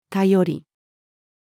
頼り-female.mp3